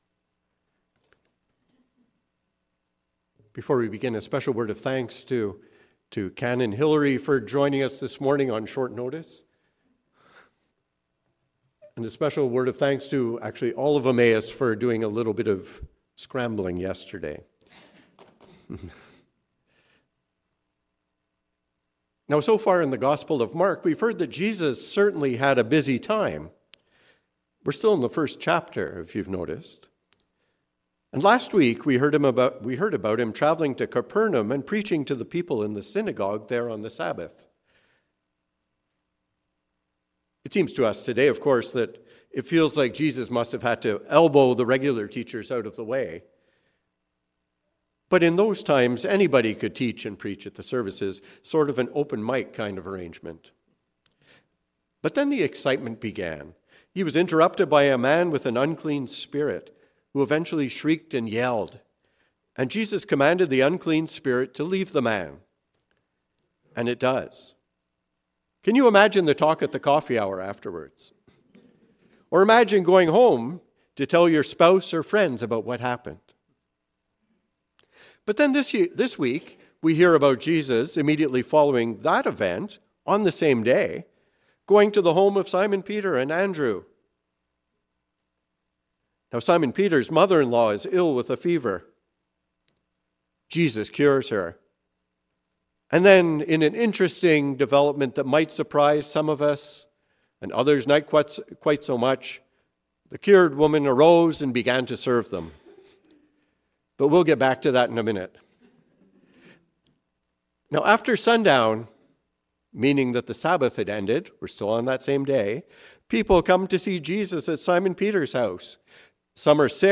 The Ministry of Restoration and Inclusion. A sermon on Mark 1:29-39.